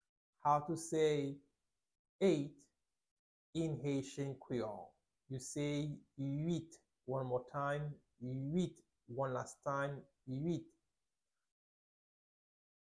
Pronunciation:
11.How-to-say-Eight-in-Haitian-Creole-–-Uit-pronunciation-.mp3